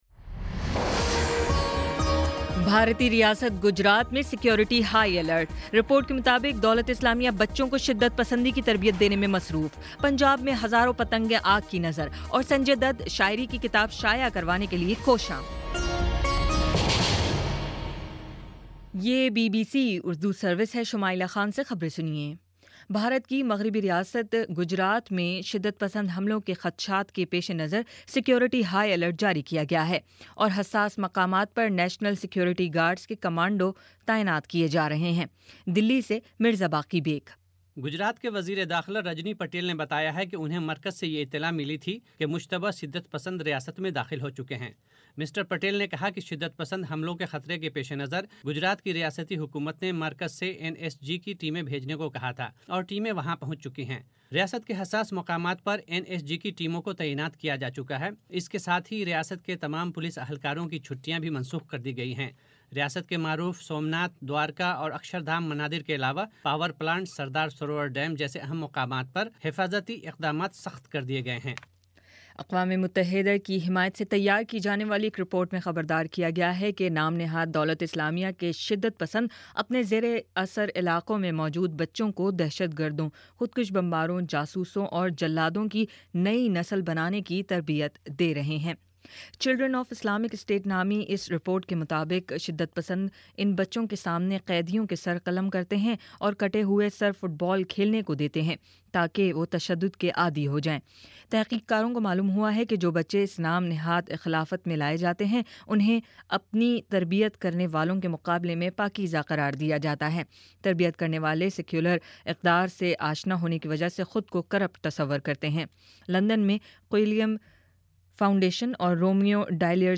مارچ 06 : شام پانچ بجے کا نیوز بُلیٹن